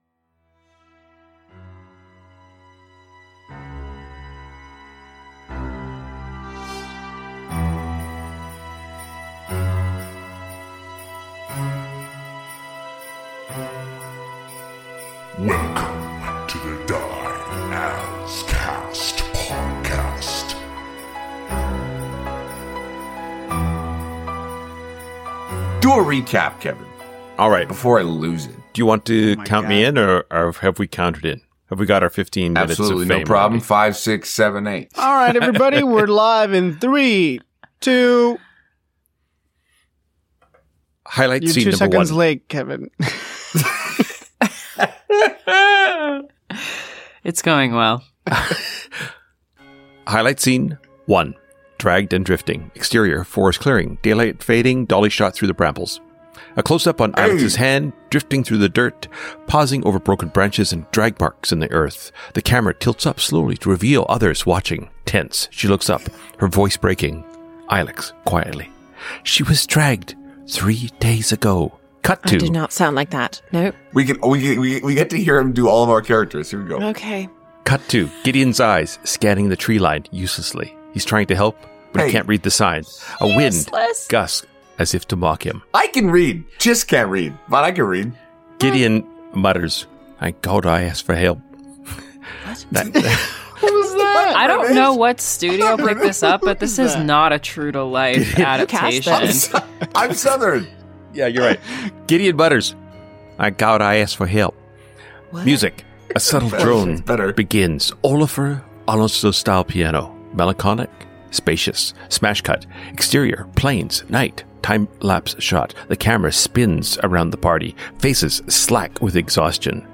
Audio Drama
Actual Play